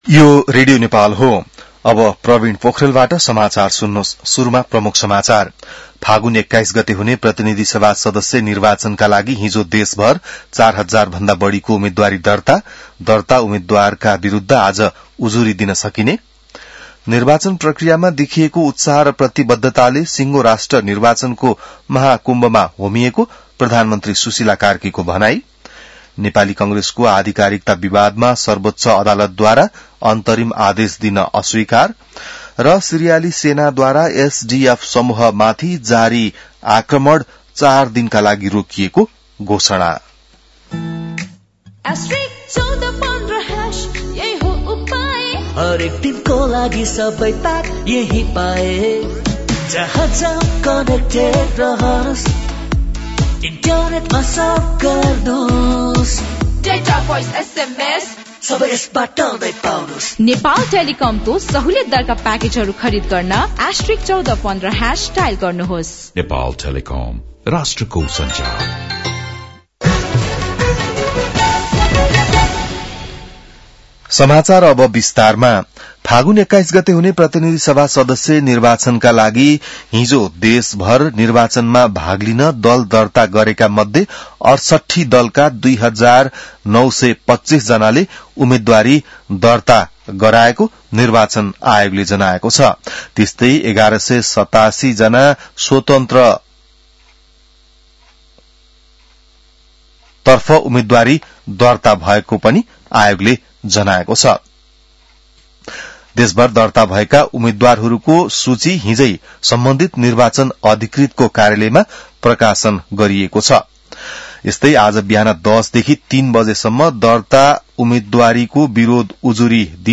बिहान ७ बजेको नेपाली समाचार : ७ माघ , २०८२